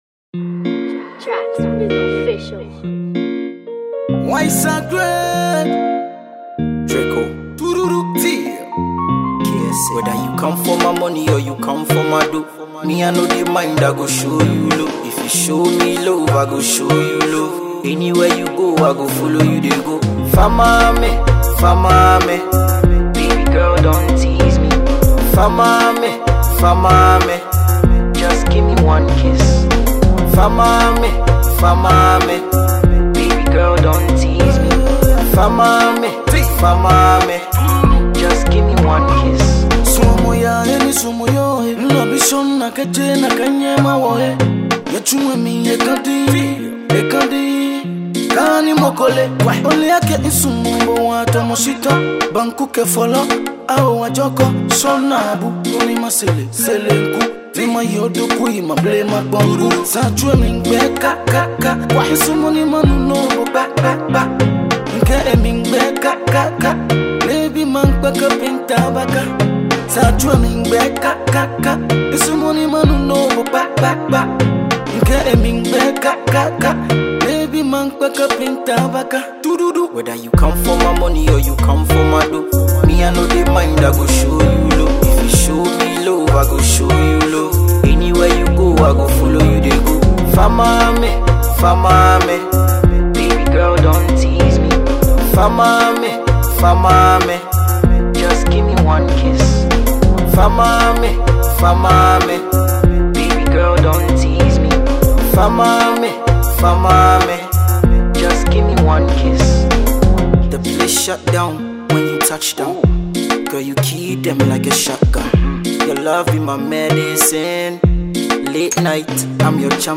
is a melodic effort
over a lurkyy afrobeat